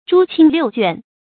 诸亲六眷 zhū qīn liù juàn
诸亲六眷发音
成语注音 ㄓㄨ ㄑㄧㄣ ㄌㄧㄨˋ ㄐㄨㄢˋ